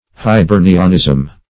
Search Result for " hibernianism" : The Collaborative International Dictionary of English v.0.48: Hibernicism \Hi*ber"ni*cism\, Hibernianism \Hi*ber"ni*an*ism\, n. An idiom or mode of speech peculiar to the Irish.